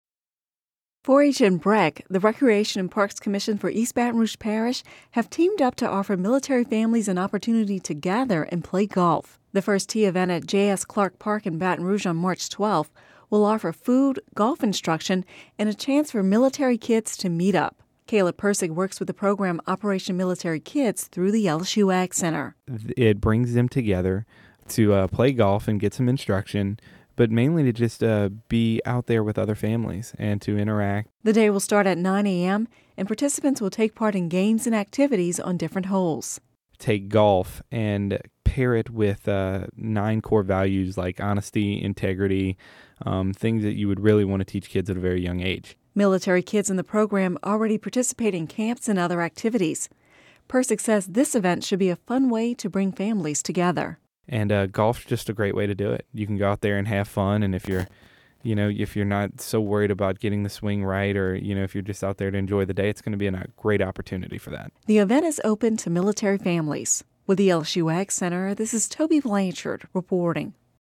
(Radio News 02/14/11) Louisiana 4-H and BREC, the recreation and parks commission for East Baton Rouge Parish, have teamed up to offer military families an opportunity to gather and play golf. The First Tee event March 12 at J.S. Clark Park in Baton Rouge will offer food, golf instruction and a chance for military kids to meet.